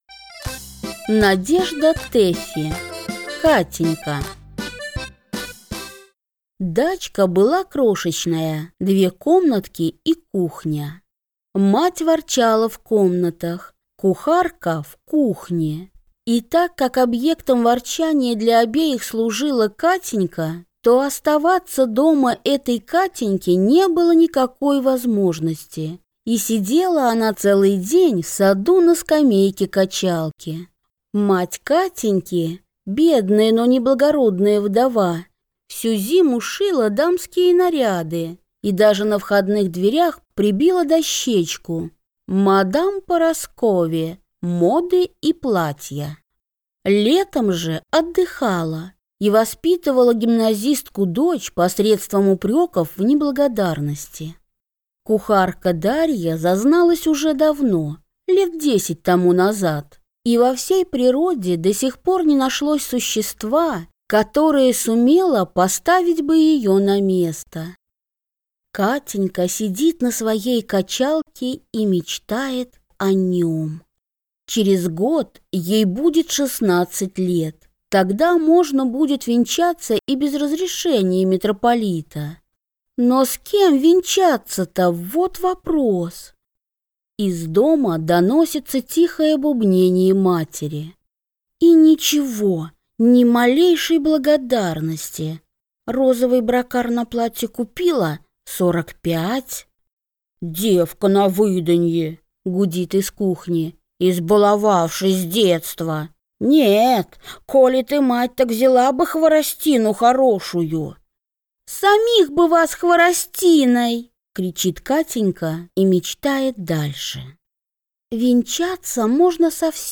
Аудиокнига Катенька | Библиотека аудиокниг